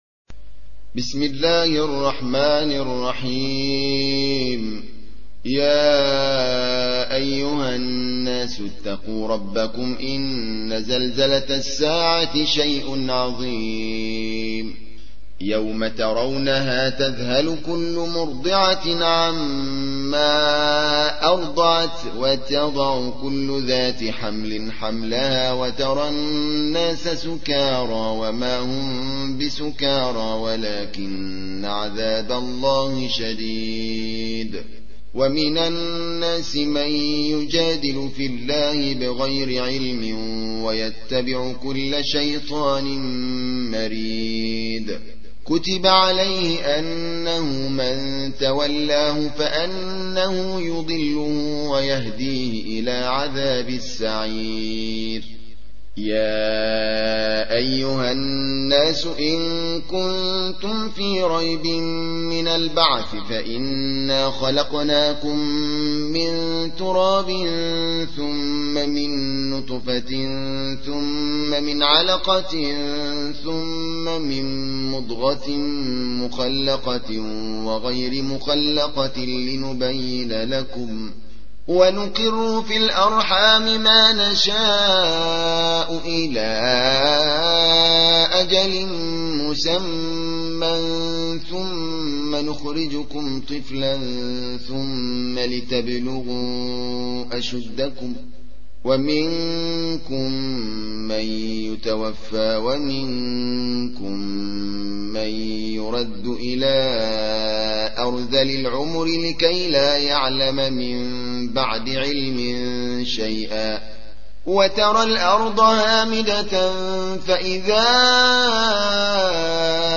موقع يا حسين : القرآن الكريم 22.